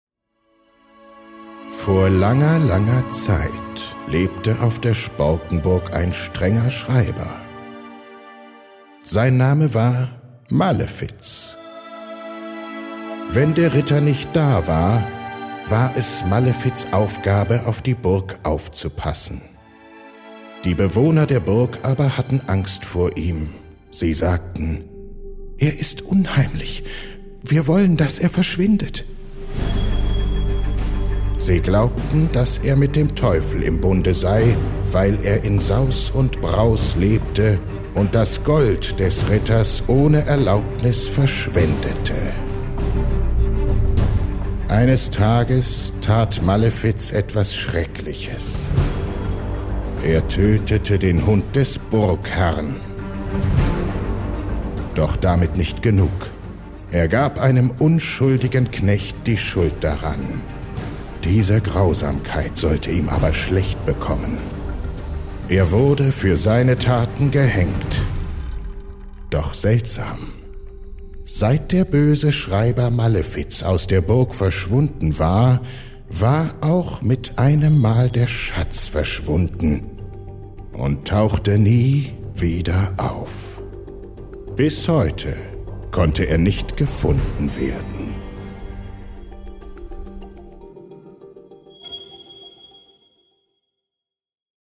Spannende Hörspiele einfach per QR-Code abrufen.
Neues Fenster: Hörprobe Sporkenburg
hoerprobe-sporkenburg-malefiz.mp3